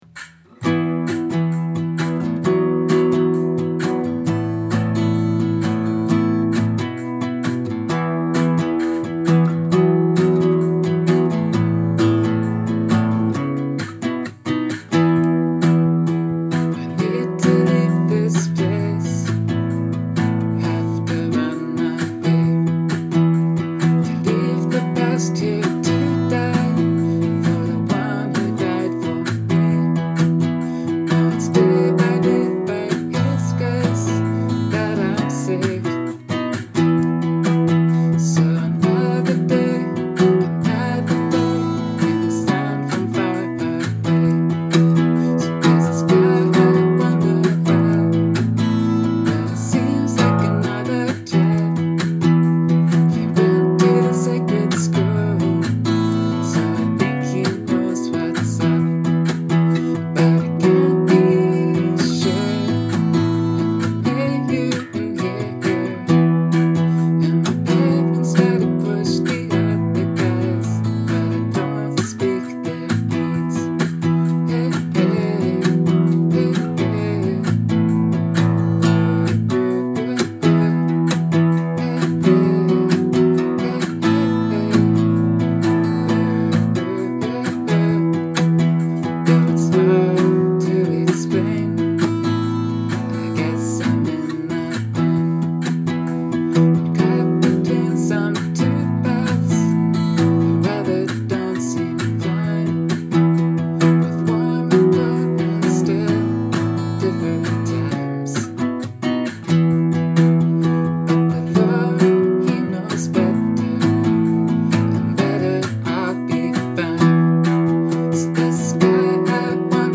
Run Away (Practice).mp3